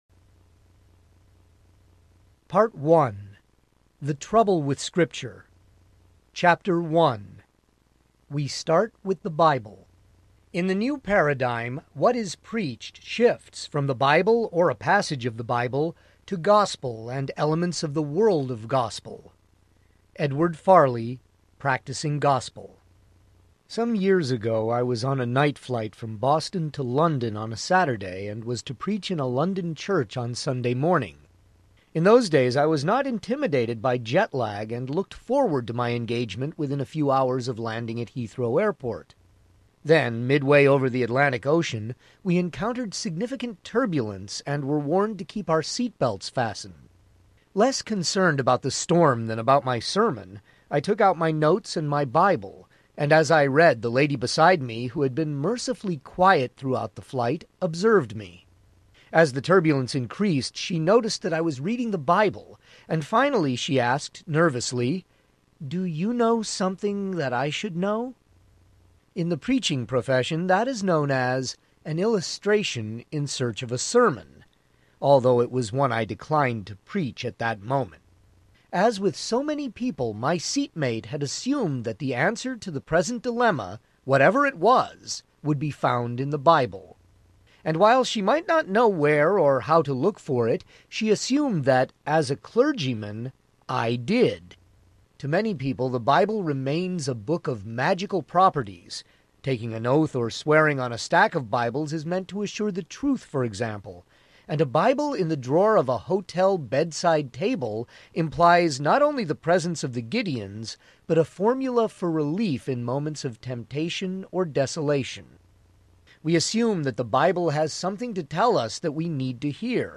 The Scandalous Gospel of Jesus Audiobook
8.0 Hrs. – Unabridged